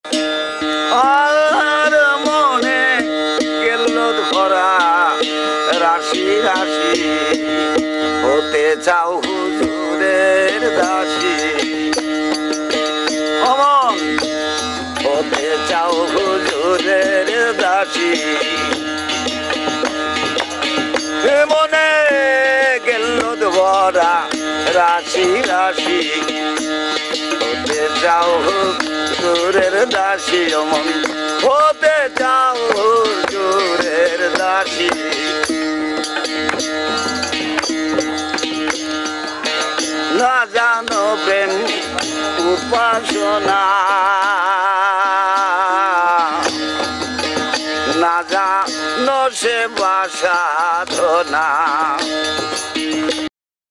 • Качество: 320, Stereo
инструментальные
индийские
барабаны
живой звук